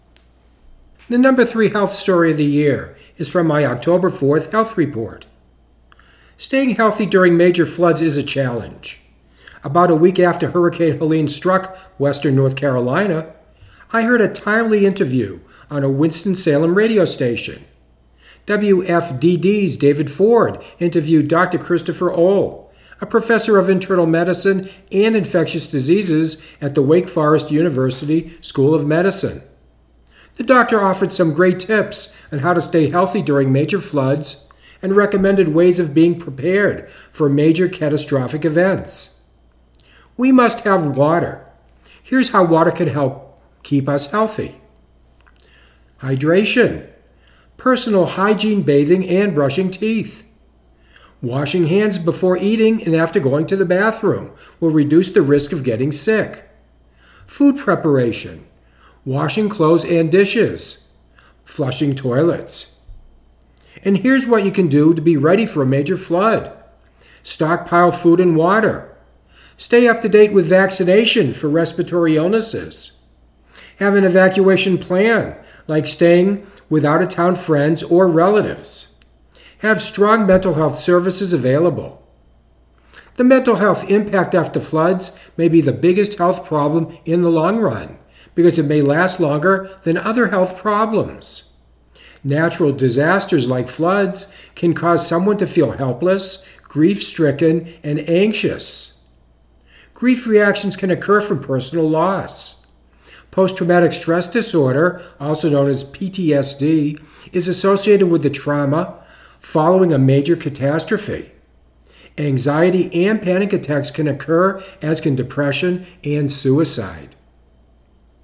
#3 Health report for 2024: Keeping healthy after floods & Be prepared